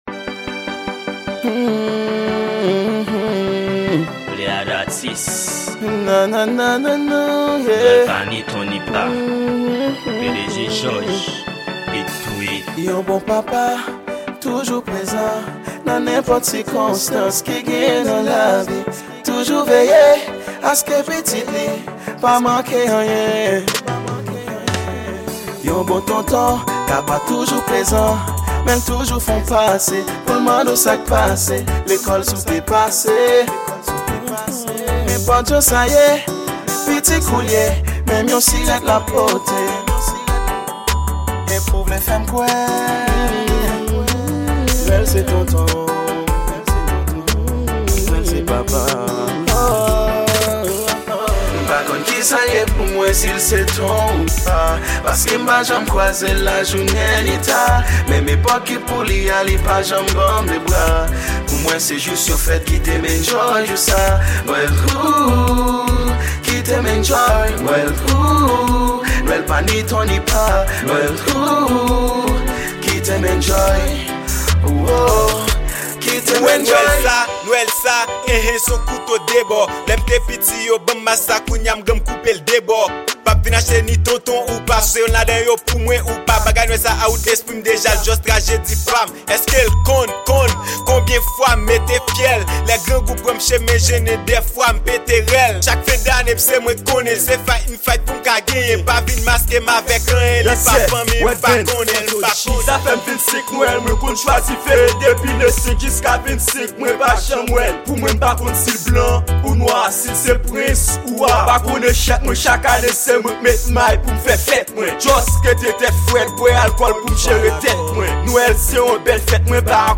Genre: NWEL.